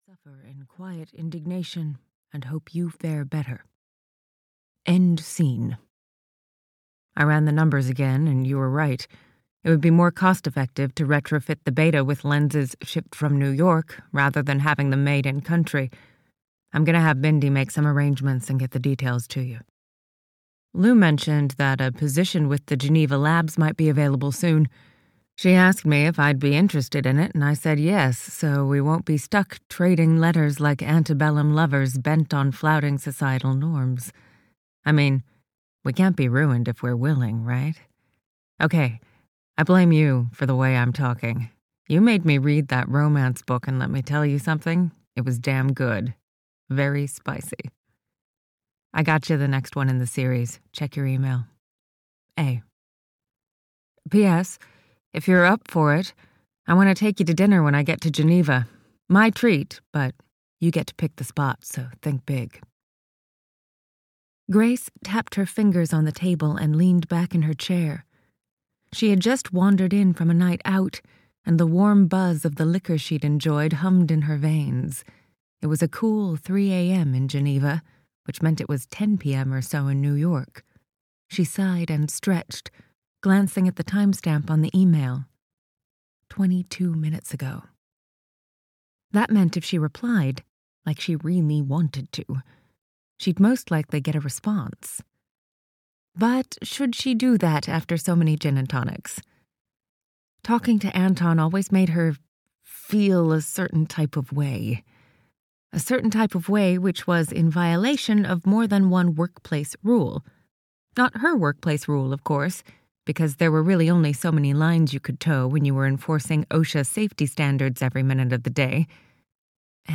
Love and Gravity (EN) audiokniha
Ukázka z knihy